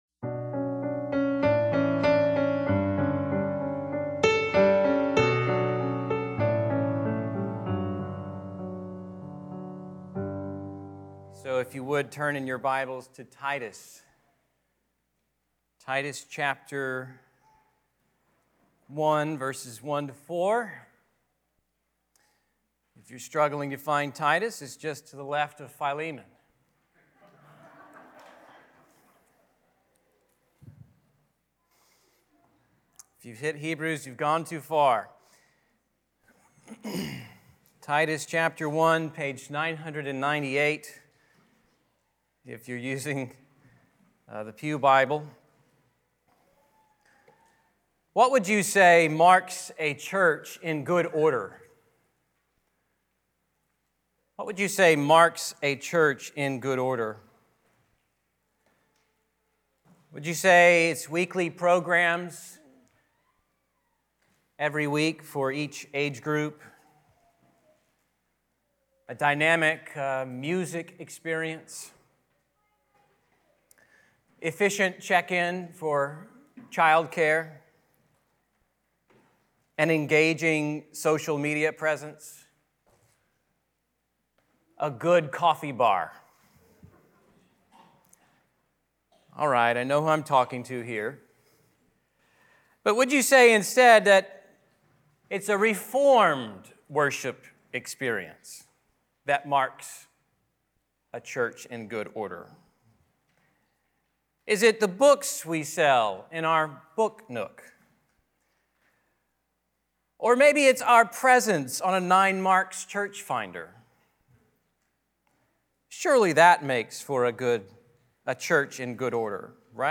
Redeemer Church Fort Worth | Sermons